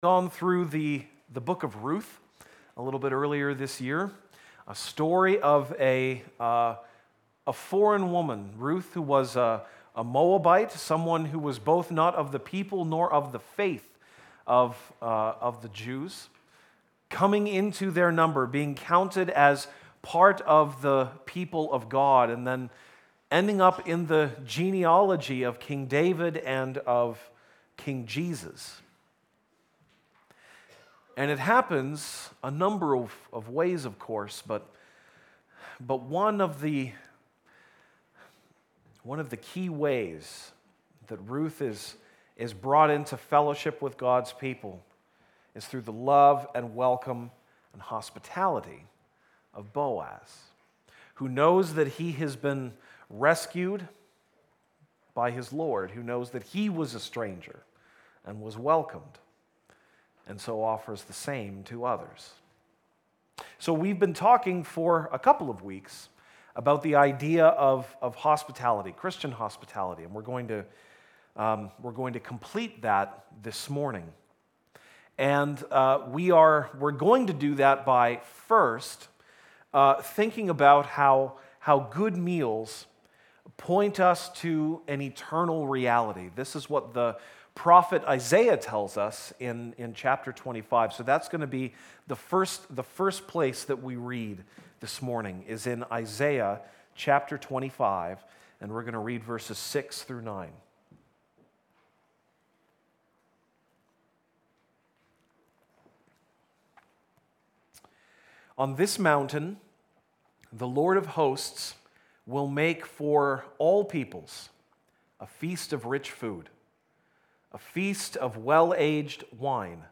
March 18, 2018 (Sunday Morning)